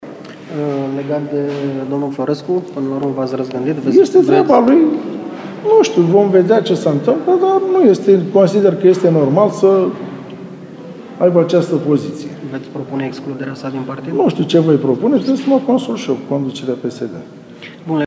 Discuții în contradictoriu vreme de mai bine de jumătate de ceas penmtru aprobarea unui singur proiect de hotărâre din Consiliul local Municipal.
Punctul de vedere al lui Florescu nu a fost îmbrățișat de primarul Constantiin Toma care și-a ieșit din fire și i s-a adresat lui Constantin Florescu pe un ton mai ridicat.
Toma-despre-Florescu.mp3